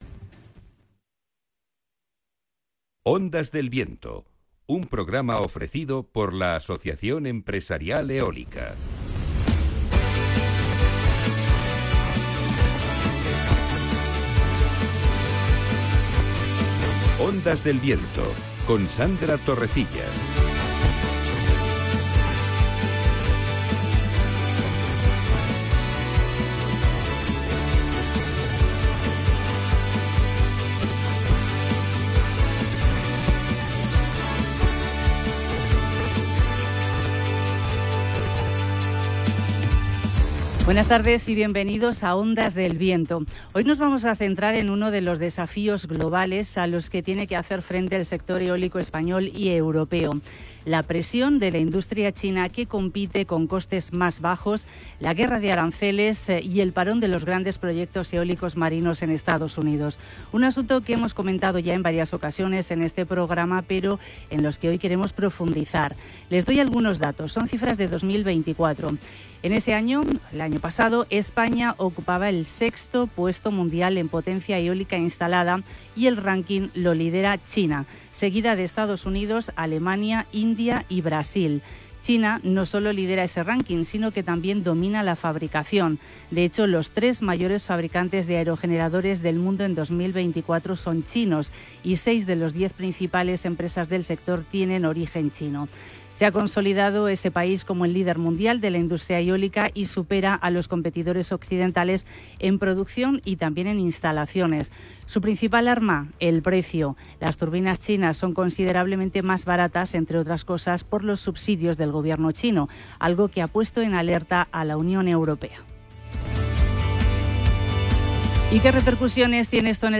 🎙Hoy 21 de octubre hemos emitido un nuevo episodio de Ondas del Viento, el programa radiofónico del sector eólico en la emisora Capital Radio. 🎙Hemos hablado sobre la situación de la industria eólica europea ante la competencia de otros mercados como es China y qué repercusiones tiene esto en el sector y cómo ha de proteger Europa su industria eólica.